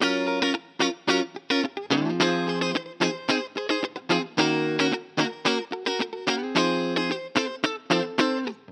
03 Guitar PT4.wav